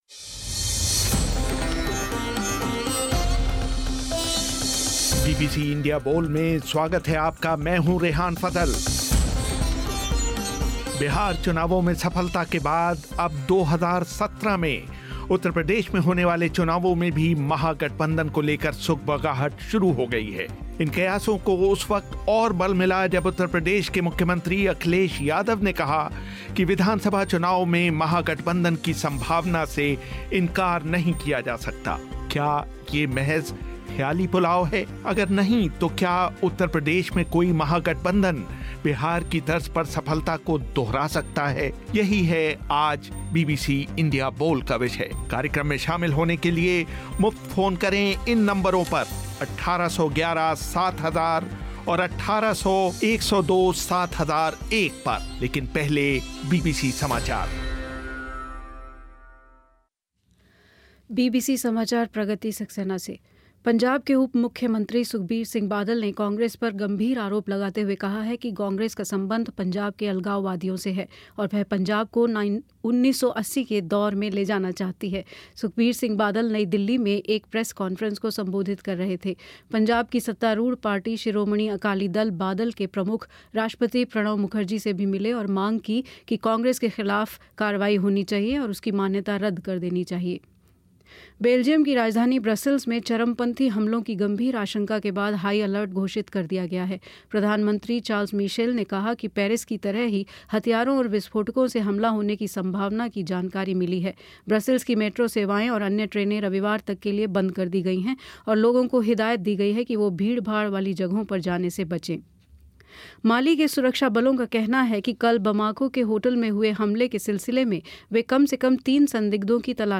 आज का विषय है- क्या बिहार की तर्ज़ पर उत्तर प्रदेश में भी कोई महागठबंधन सफलता दोहरा सकता है ? श्रोताओं से बातचीत करने के लिए स्टूडियो में होंगे